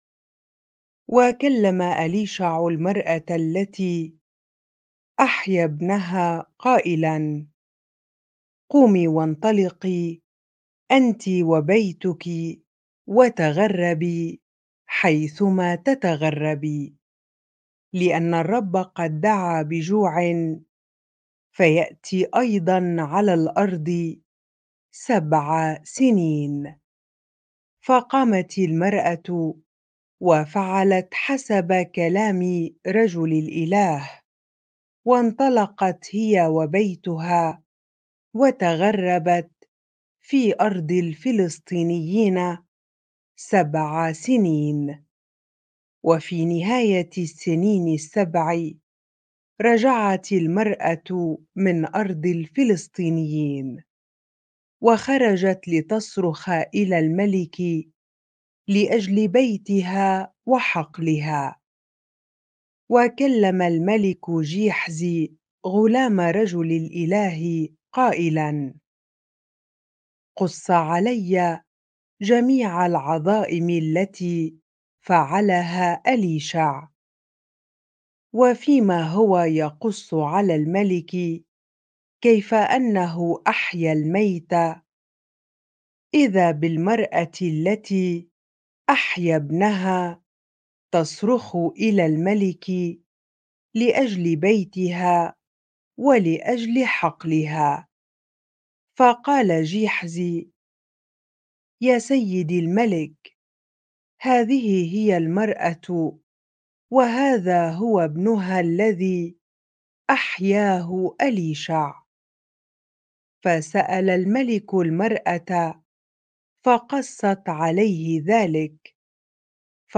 bible-reading-2 Kings 8 ar